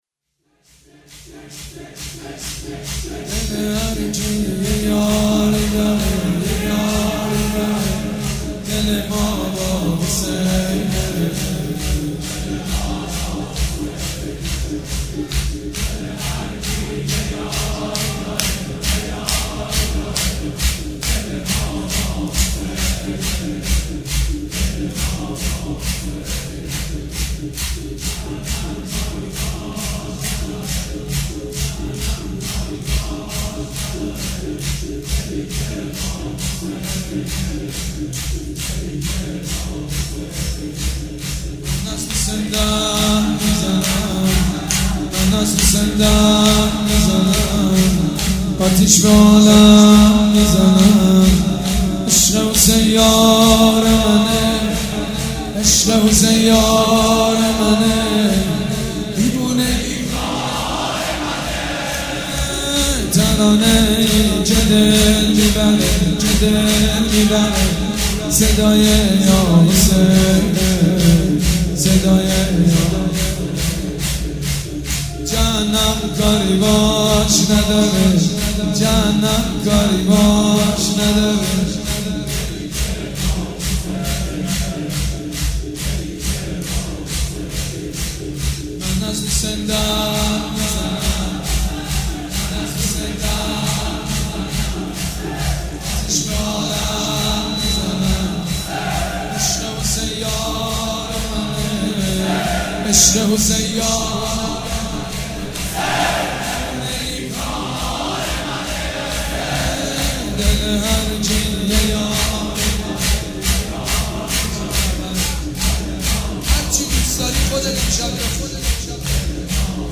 شب یازدهم محرم الحرام‌
شور
مداح
حاج سید مجید بنی فاطمه
مراسم عزاداری شب شام غریبان